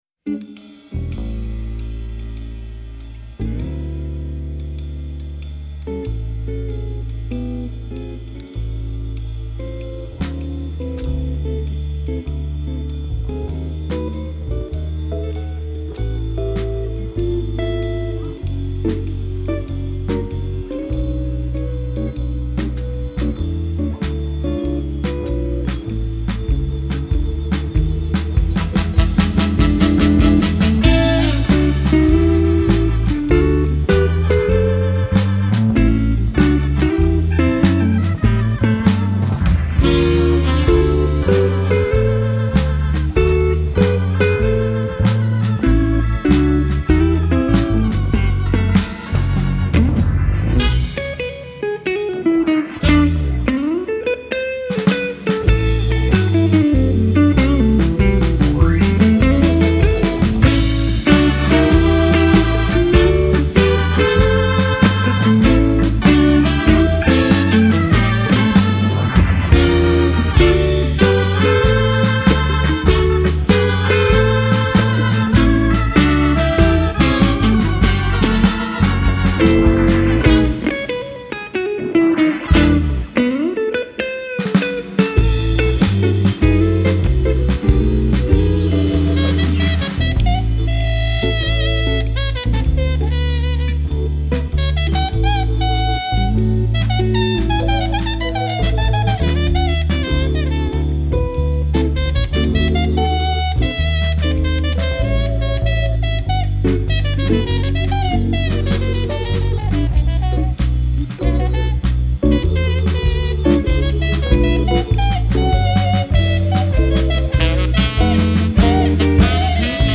2260   04:11:00   Faixa:     Jazz